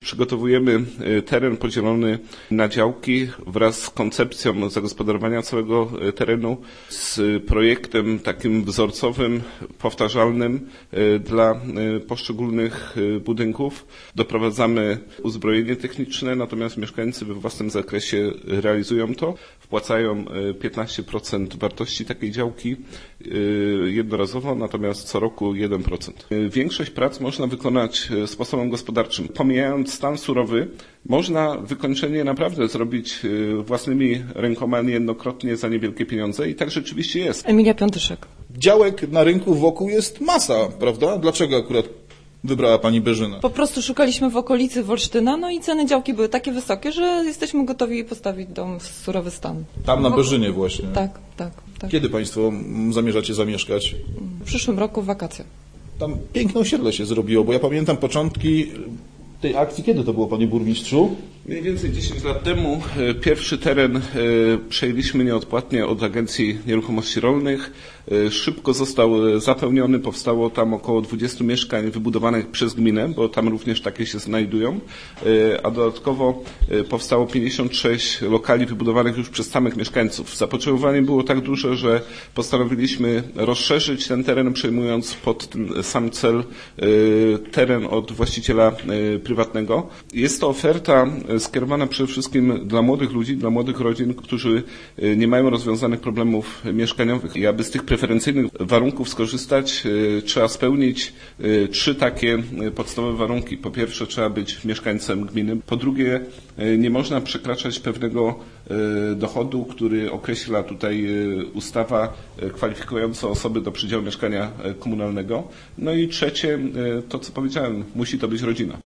W Wolsztynie był nasz reporter, który rozmawiał na ten temat z burmistrzem Wolsztyna Andrzejem Rogozińskim.